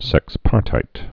(sĕks-pärtīt)